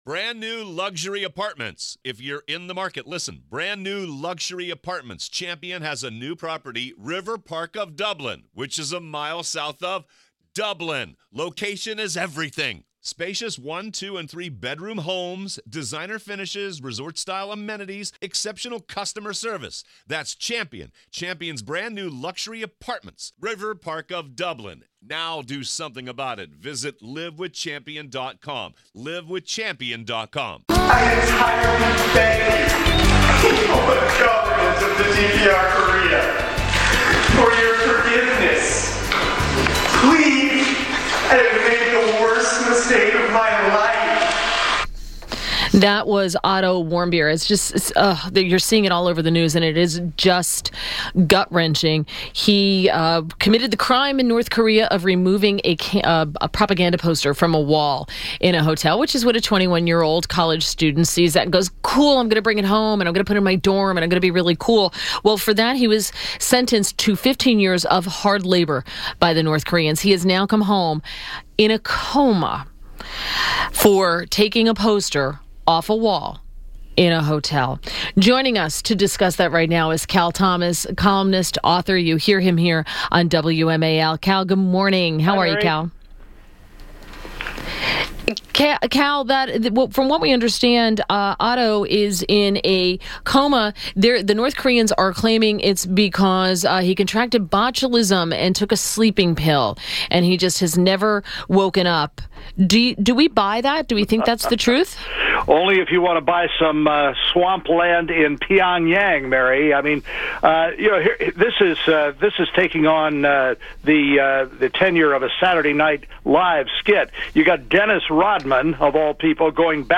WMAL Interview - CAL THOMAS 06.14.17